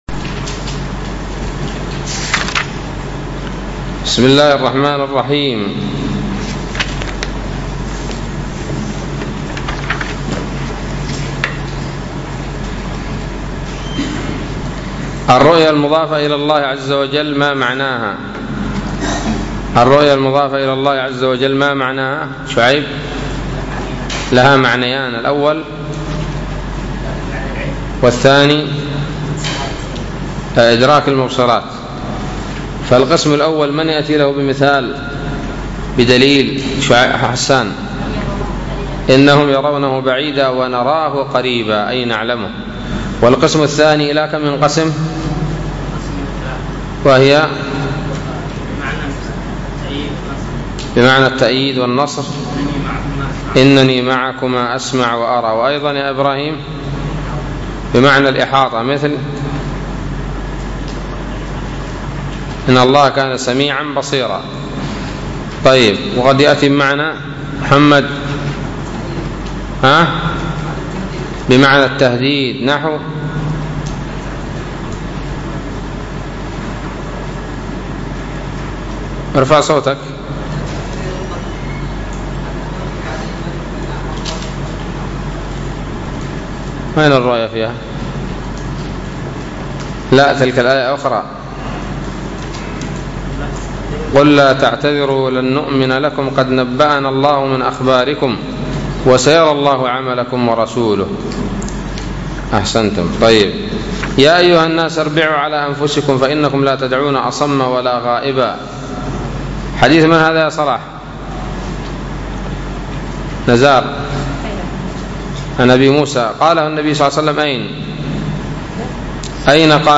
الدرس الرابع والستون من شرح العقيدة الواسطية